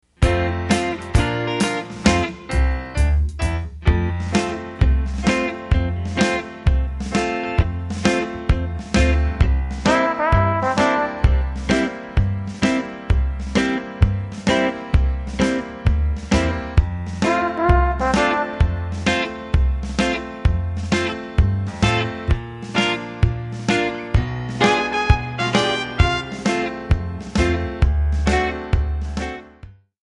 Backing track files: 1950s (275)